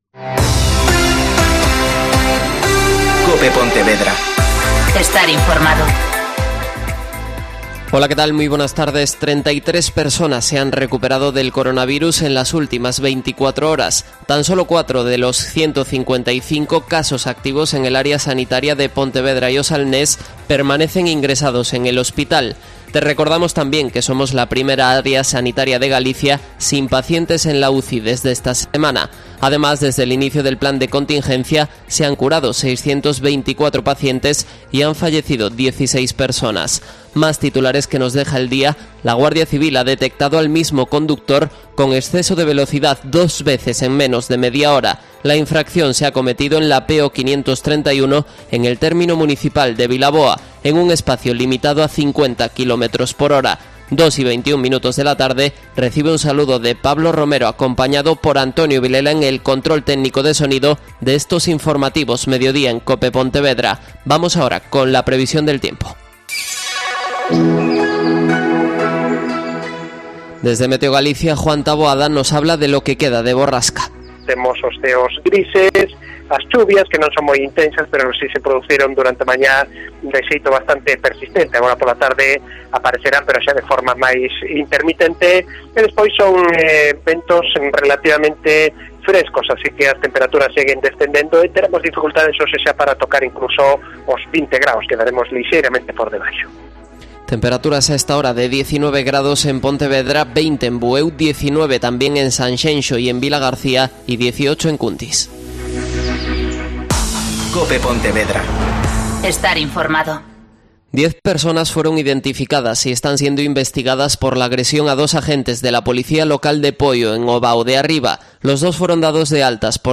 Mediodía COPE Pontevedra (Informativos 14:20h)